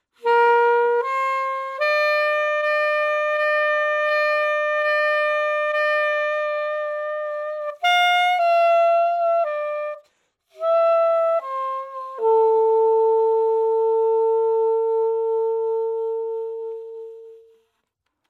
Original sound, tempo: 55bpm (mp3, 286k)